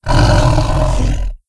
c_sibtiger_slct.wav